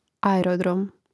aeròdrom aerodrom